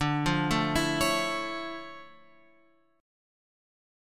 D Minor Major 9th